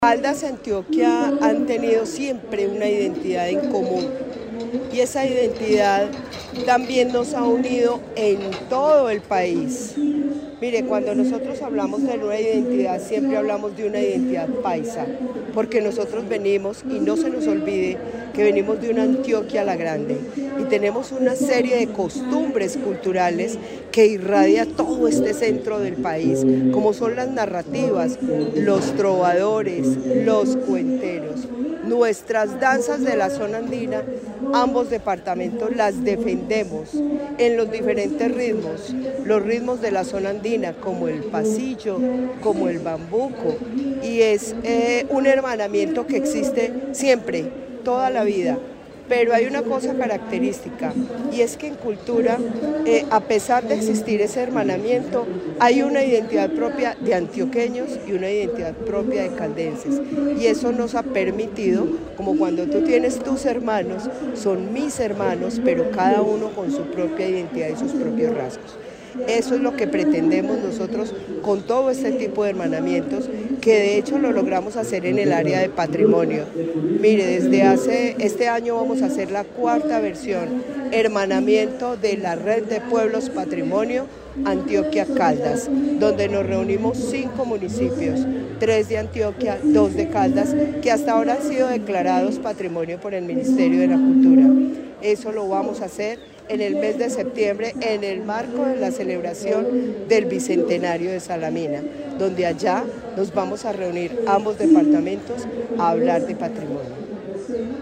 Luz Elena Castaño Rendón, secretaria de Cultura de Caldas